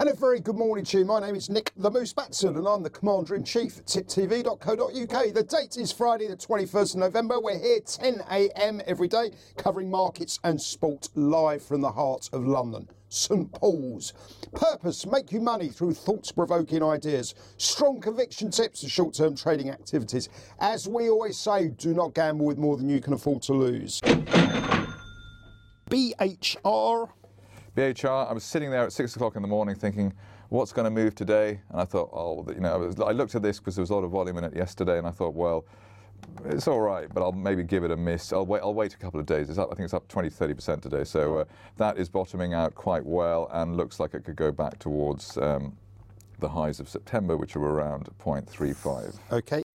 Live Market Round Up & Soapbox Thoughts